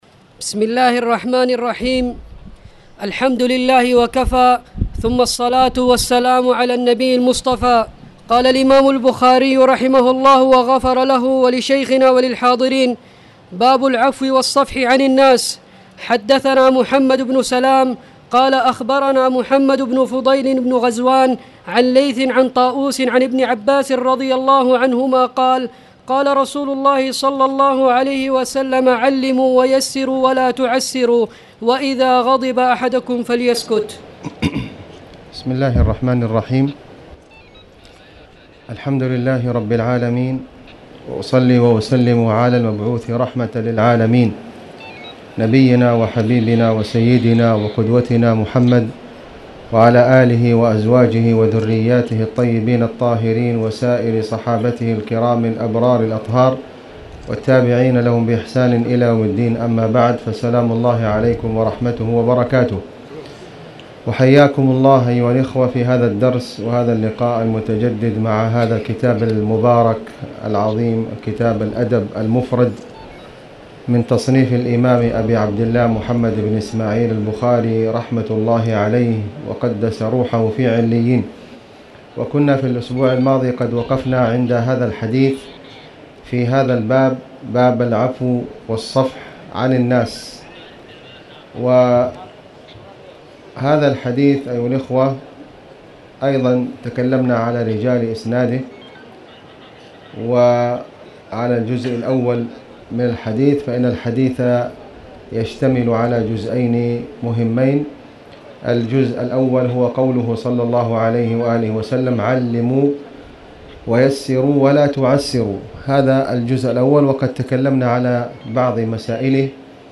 تاريخ النشر ٦ ربيع الأول ١٤٣٨ هـ المكان: المسجد الحرام الشيخ: خالد بن علي الغامدي خالد بن علي الغامدي باب العفو والصفح عن الناس